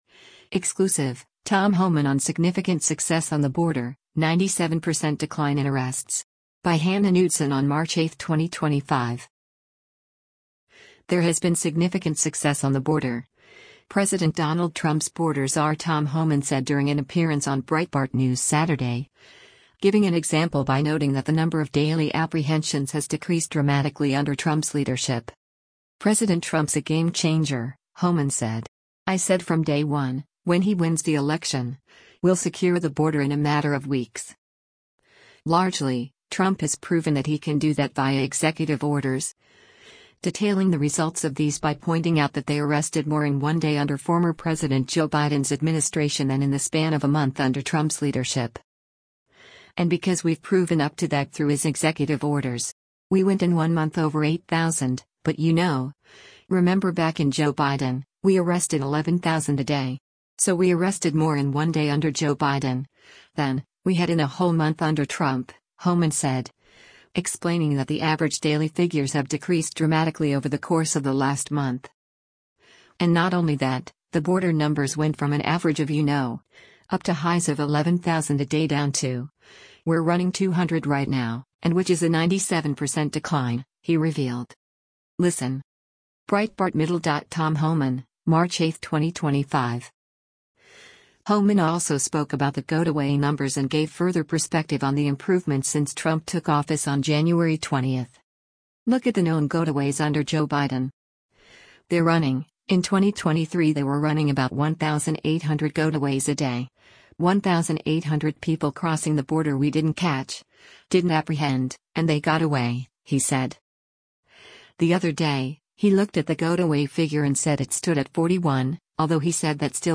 There has been “significant success” on the border, President Donald Trump’s border czar Tom Homan said during an appearance on Breitbart News Saturday, giving an example by noting that the number of daily apprehensions has decreased dramatically under Trump’s leadership.
Breitbart News Saturday airs on SiriusXM Patriot 125 from 10:00 a.m. to 1:00 p.m. Eastern.